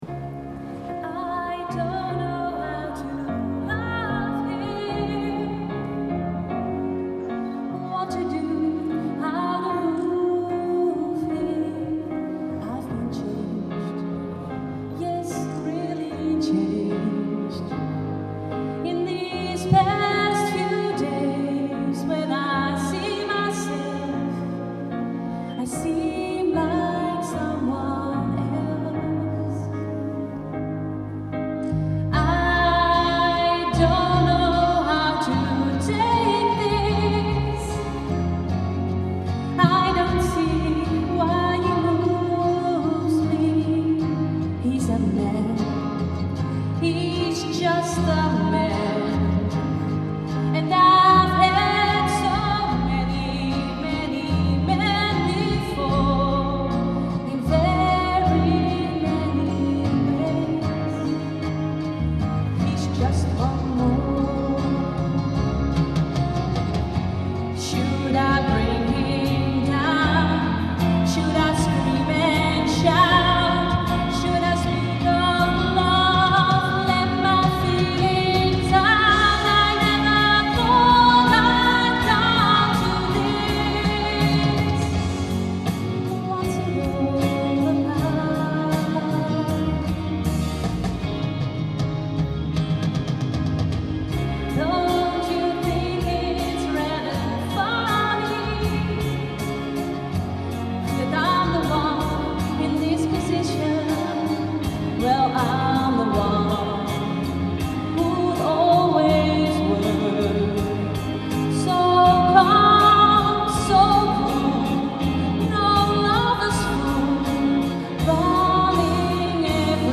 Wekelijkse concerten in de Manhuistuin in Goes
Optreden Musicalvrienden en Jeugdorkest Eigenwies - 19 mei 2019